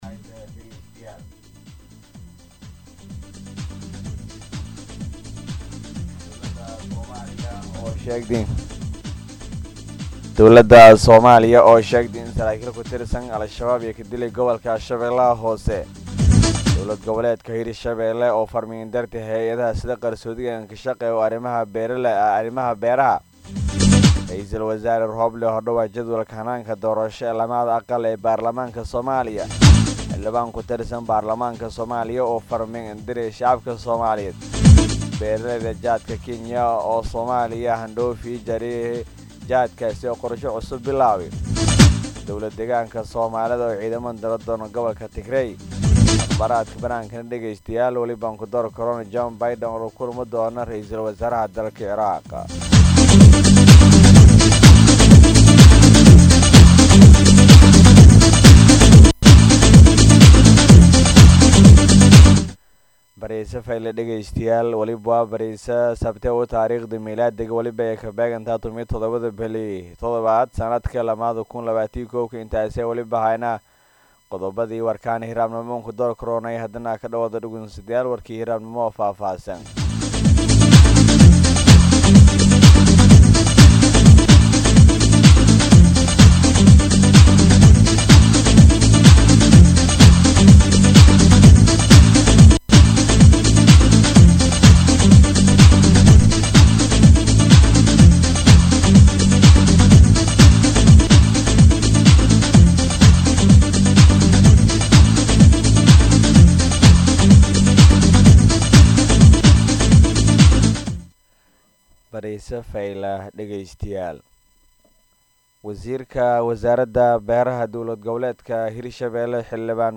warka-subaxnimo.mp3